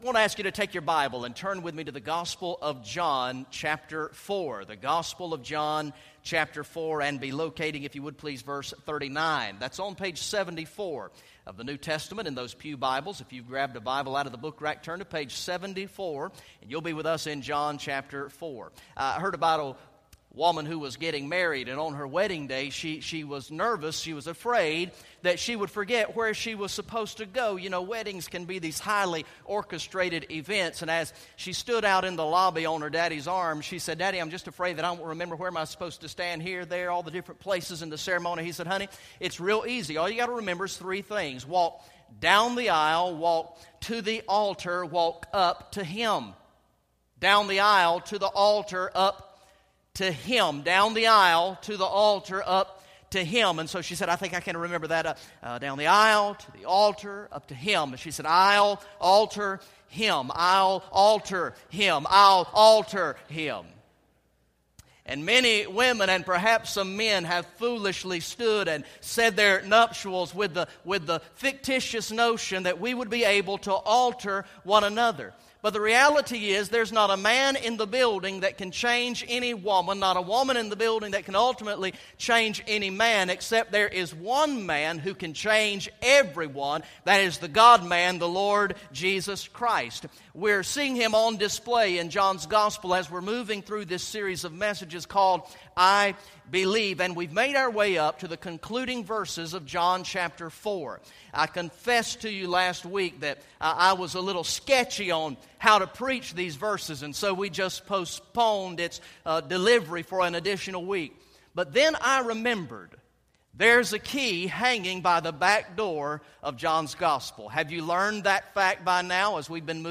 Message #15 from the sermon series through the gospel of John entitled "I Believe" Recorded in the morning worship service on Sunday, July 20, 2014